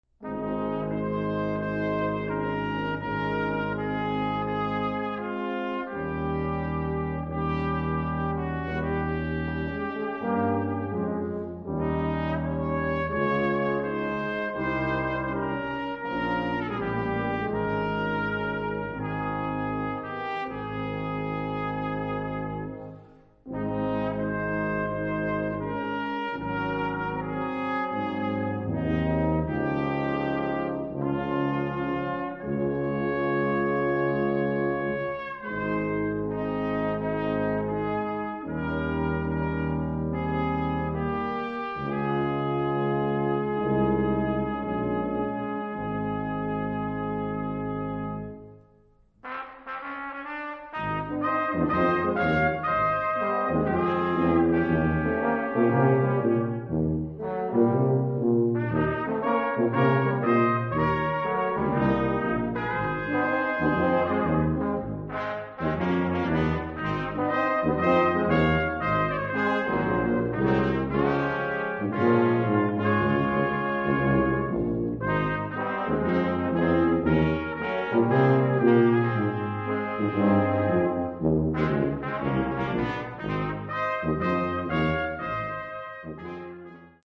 JAZZY SELECTIONS